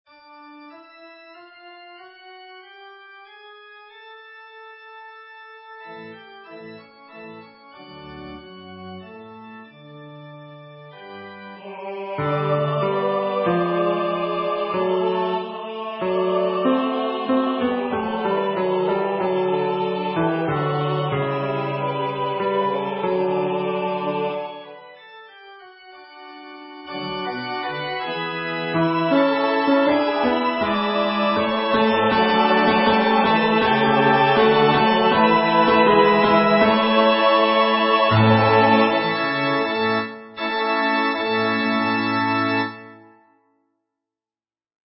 Tenor:
Edition notes: This edition was abbreviated to take about the same time as a Gregorian Chant Mass. Also, it was transposed for typical choir ranges.
SchubertMassGAbrvBeneTenrP.mp3